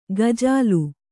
♪ gajālu